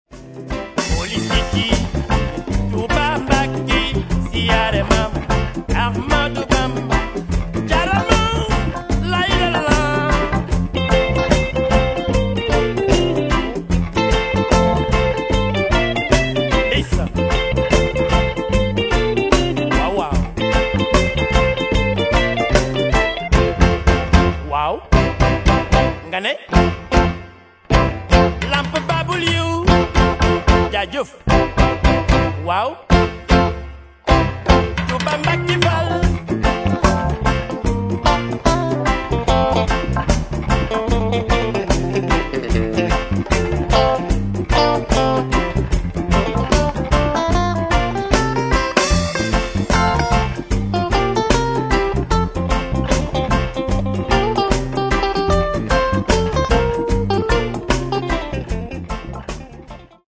Hip Hop, Reggae, Funk, Latin Music, World Music
bouncy, infectious groove of African reggae
on a highly dancable note."